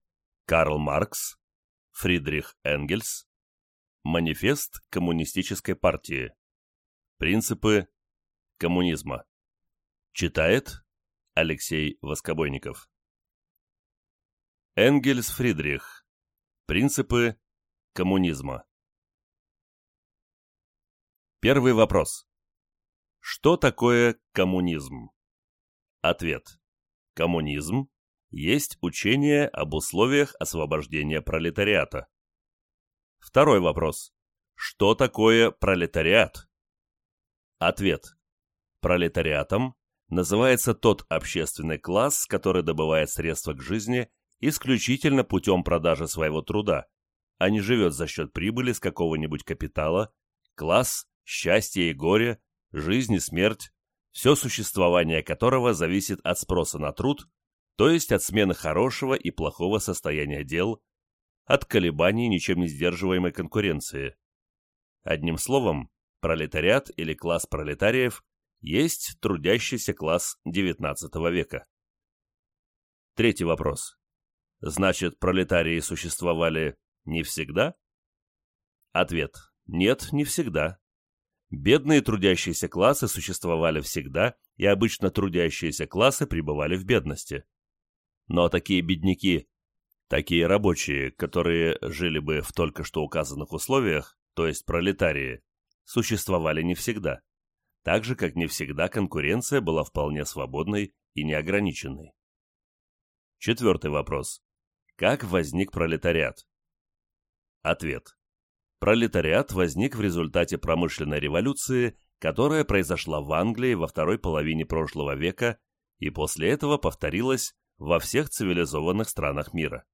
Аудиокнига Принципы коммунизма. Манифест Коммунистической партии | Библиотека аудиокниг